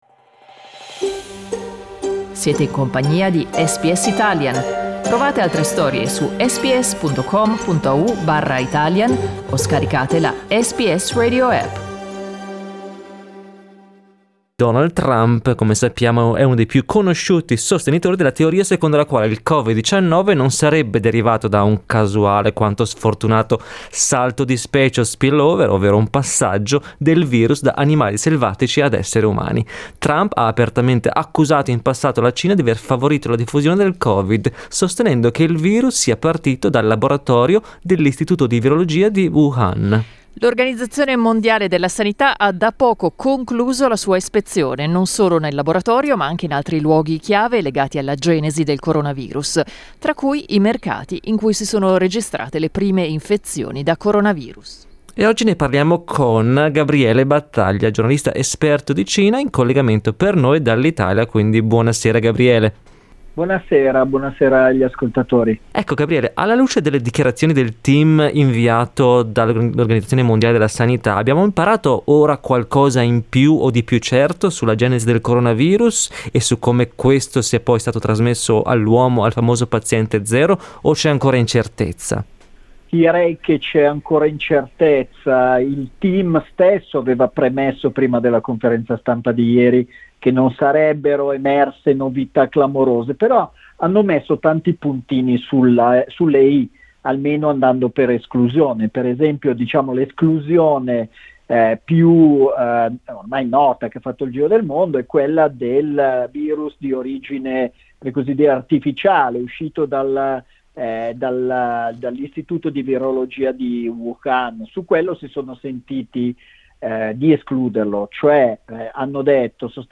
giornalista esperto di Cina